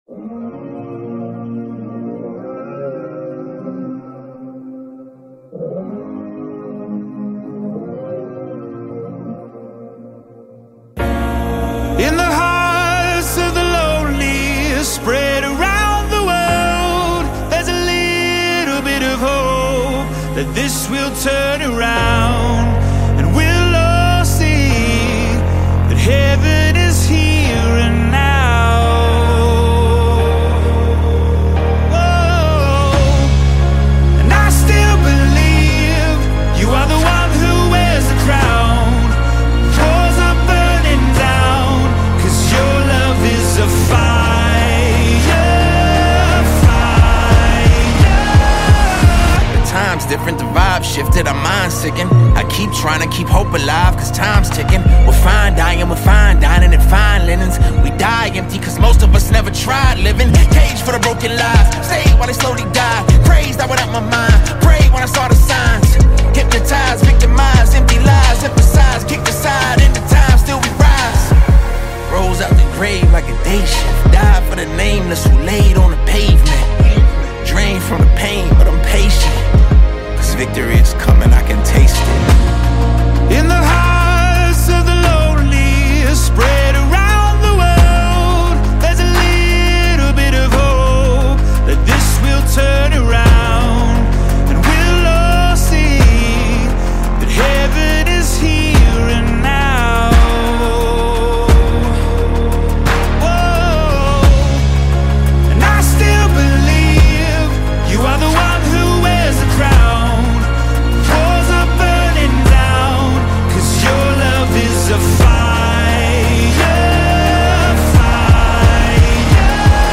poignant anthem